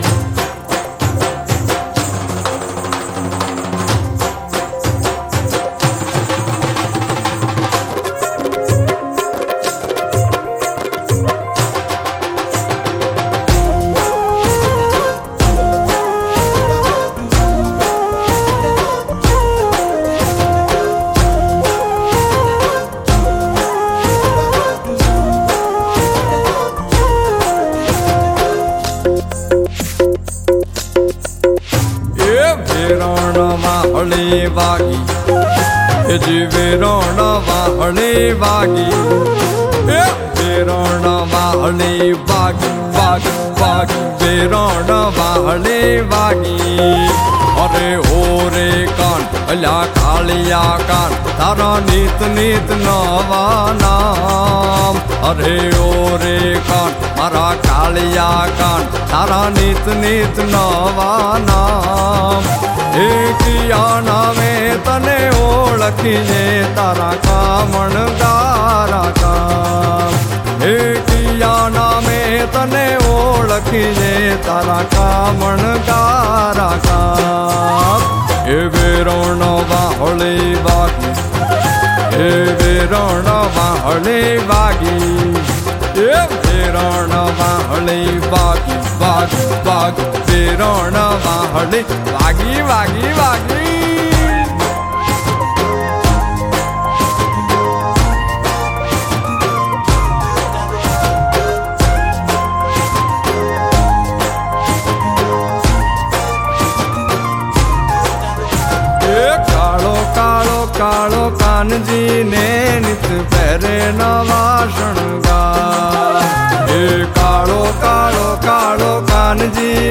Gujarati Garba